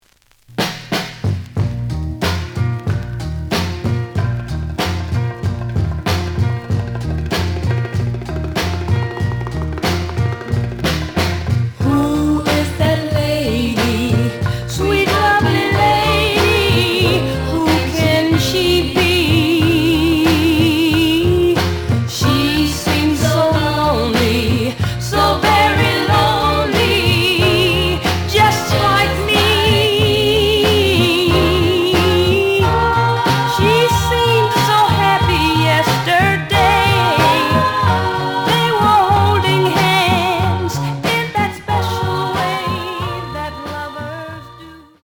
The audio sample is recorded from the actual item.
●Genre: Soul, 70's Soul